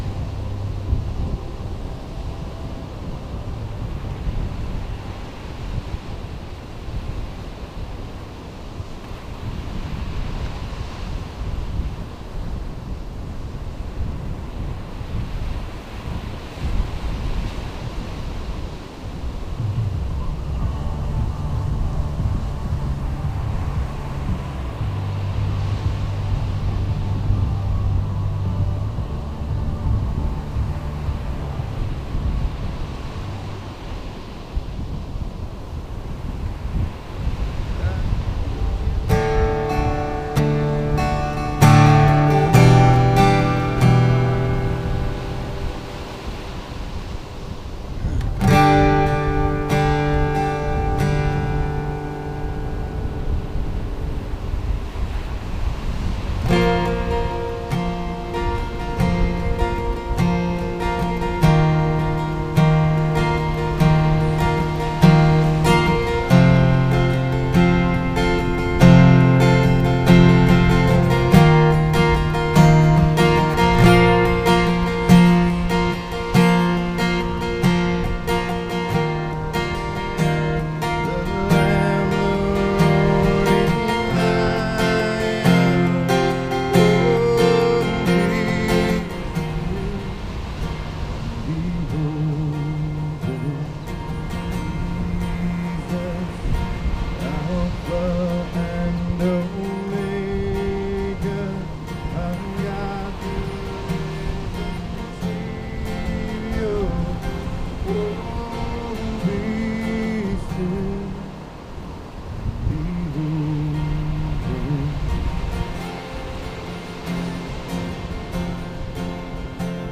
SERMON DESCRIPTION We are told to continually pray for others who are experiencing personal struggles or are in difficult circumstances.